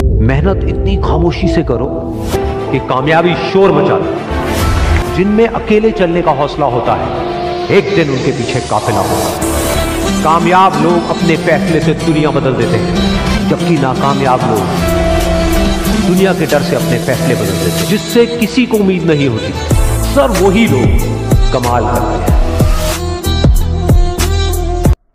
⚠🔥Breaking Glass Bottles! 🌈 Crashing sound effects free download
Crunchy And Soft Things Bottles ASMR